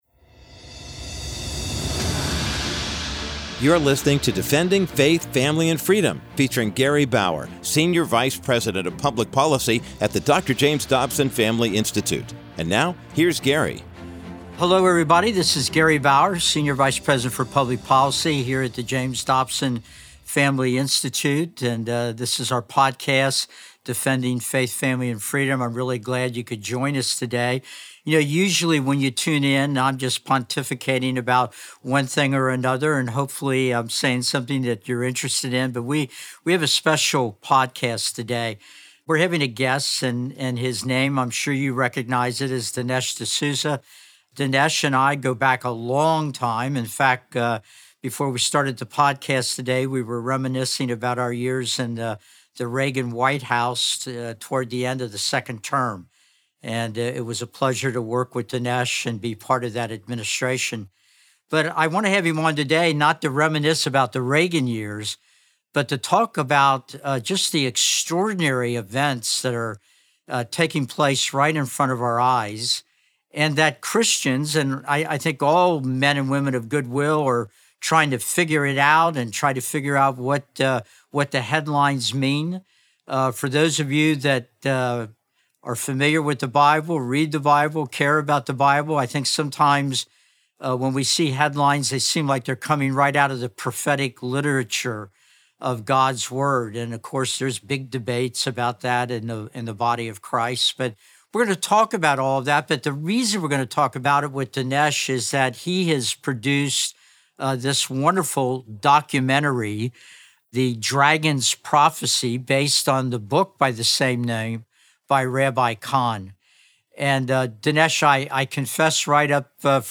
In this Defending Faith, Family, and Freedom conversation, Gary Bauer sits down with award-winning filmmaker Dinesh D’Souza to discuss his powerful new documentary, The Dragon's Prophecy—based on the best-selling book by Rabbi Jonathan Cahn.